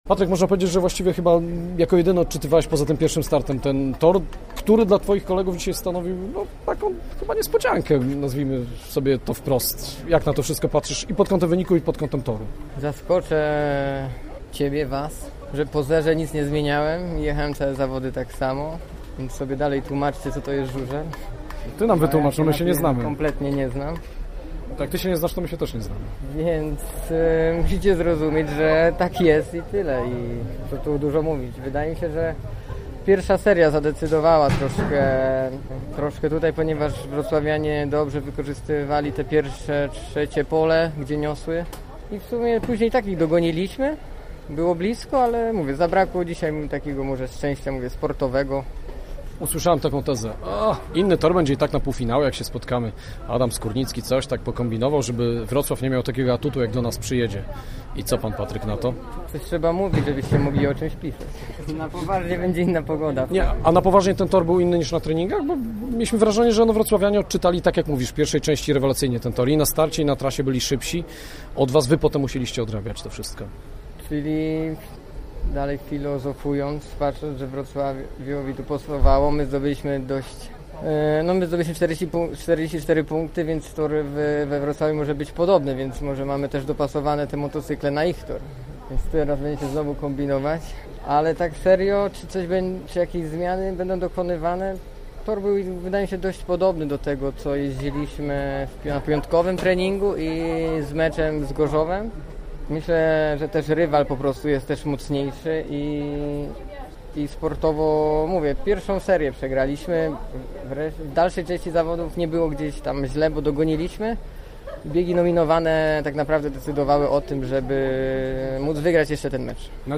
My prezentujemy rozmowy z naszymi żużlowcami: